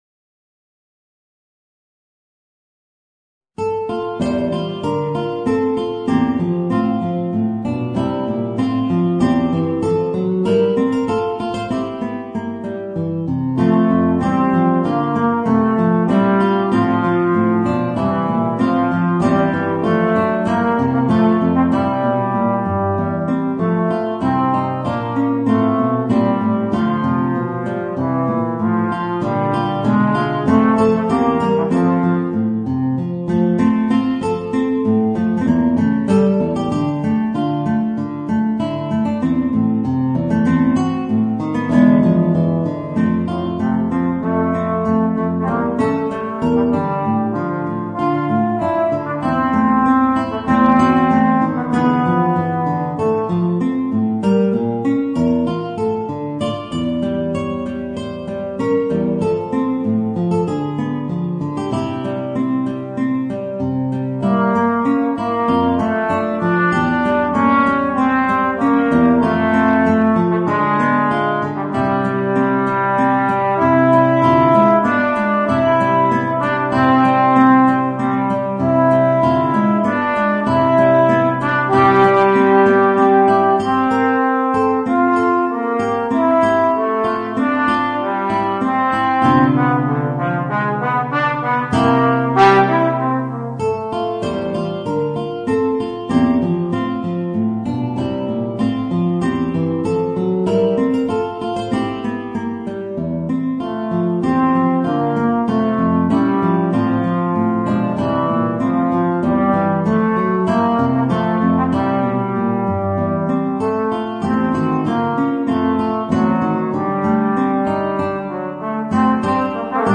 Voicing: Guitar and Trombone